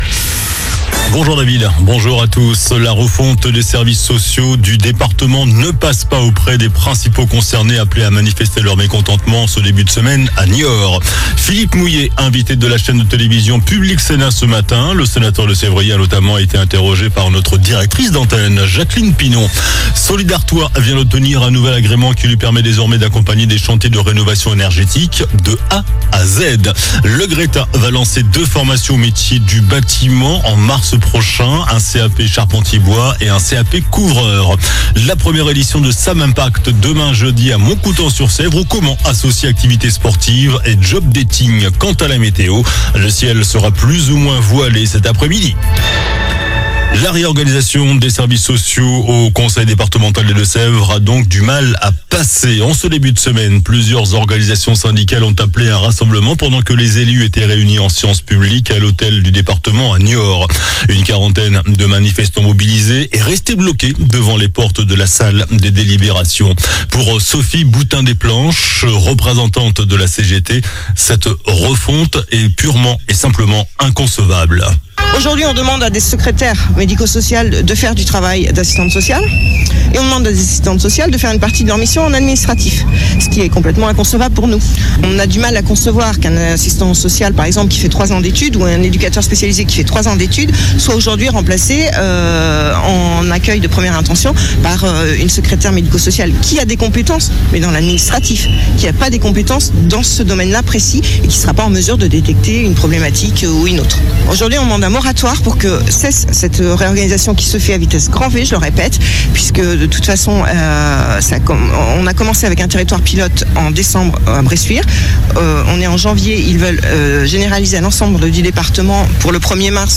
JOURNAL DU MERCREDI 12 FEVRIER ( MIDI )